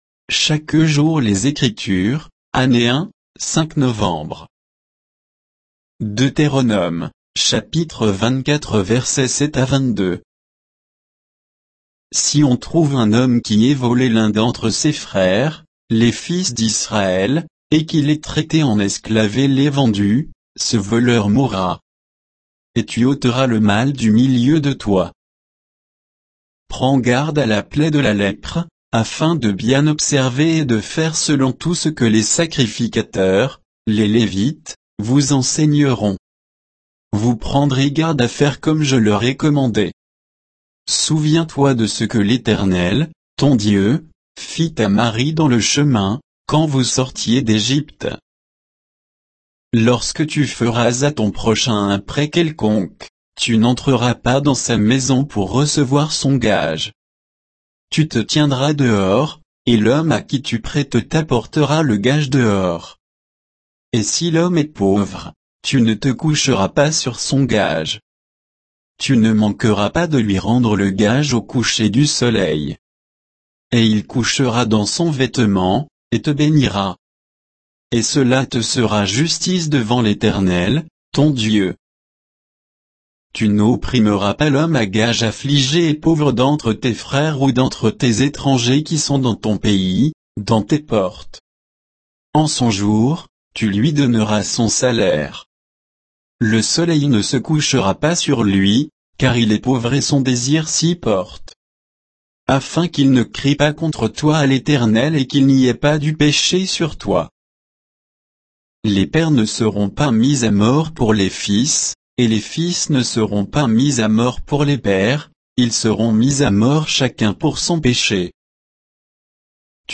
Méditation quoditienne de Chaque jour les Écritures sur Deutéronome 24, 7 à 22